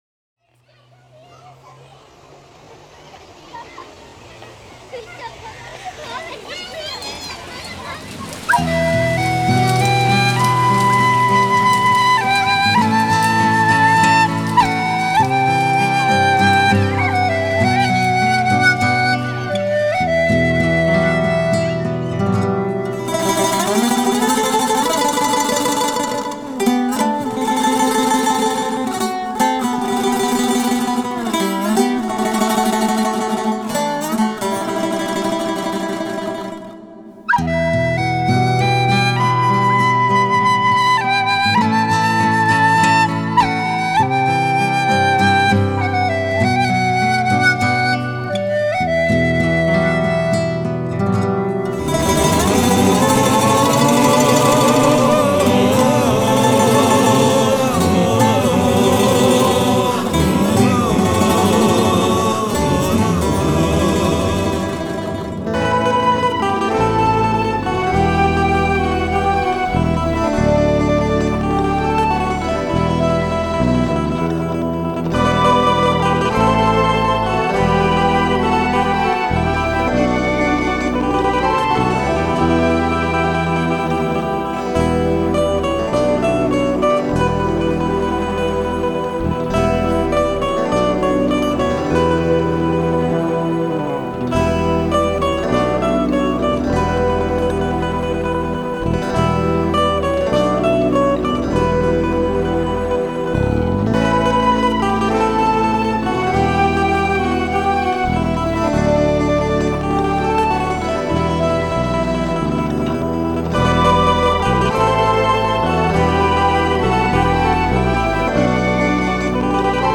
موسیقی بی کلام آرامش بخش عصر جدید